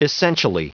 Prononciation du mot essentially en anglais (fichier audio)
Prononciation du mot : essentially